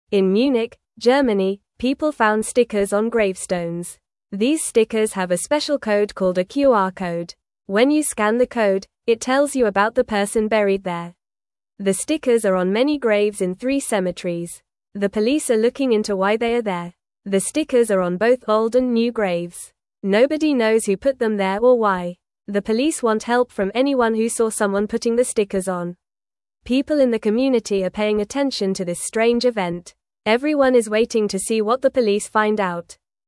Fast
English-Newsroom-Beginner-FAST-Reading-Stickers-on-Graves-Tell-Stories-of-the-Past.mp3